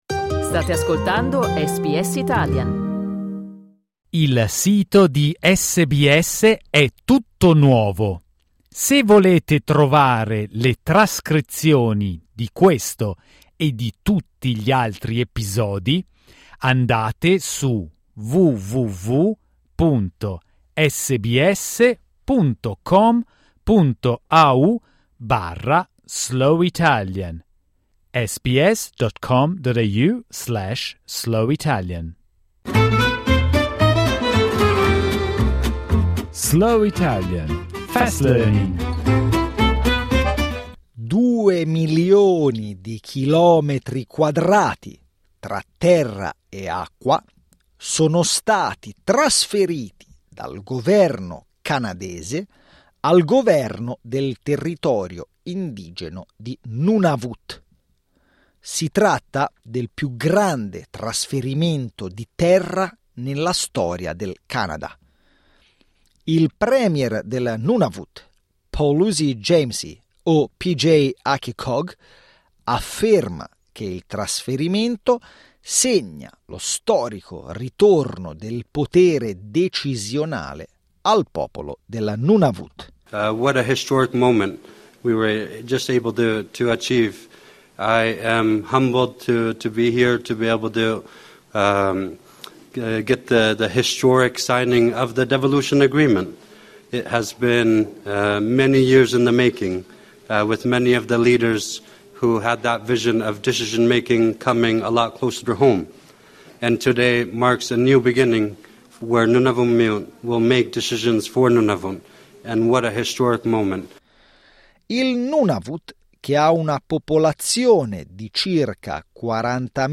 Slow Italian, Fast Learning